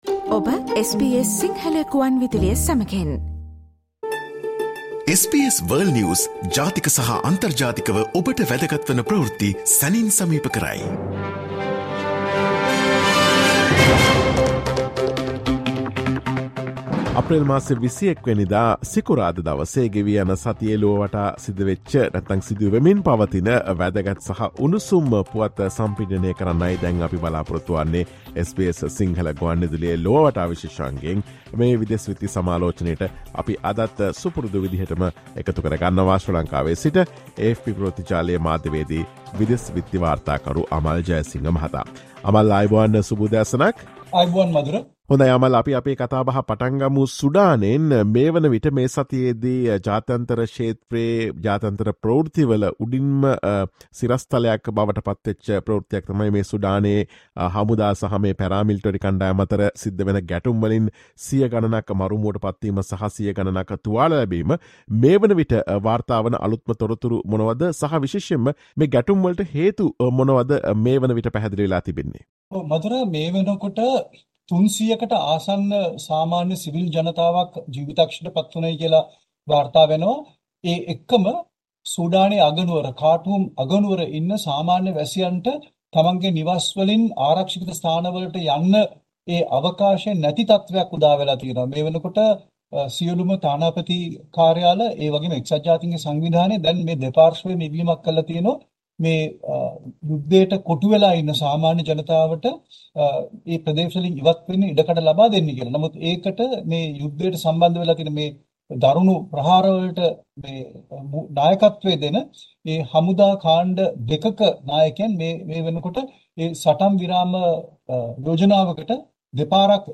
World's prominent news highlights in 15 minutes - listen to the SBS Sinhala Radio weekly world News wrap every Friday.